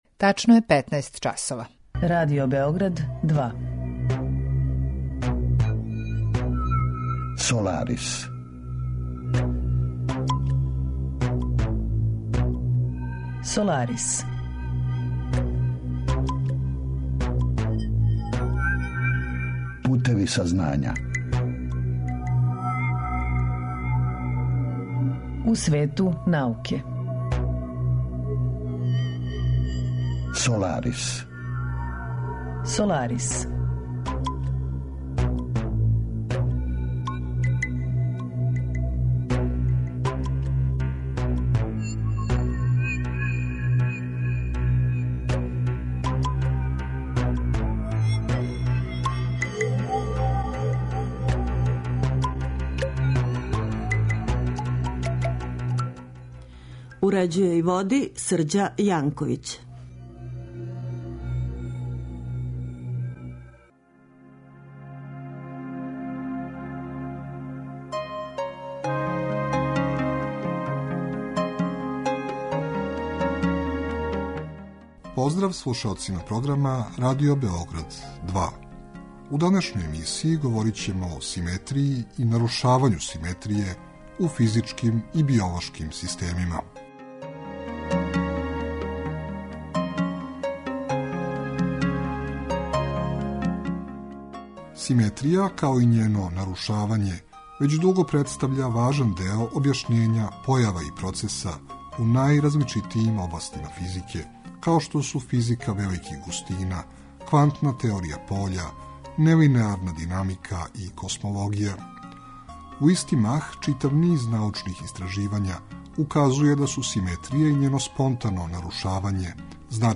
Саговорник